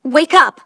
synthetic-wakewords
ovos-tts-plugin-deepponies_Starlight_en.wav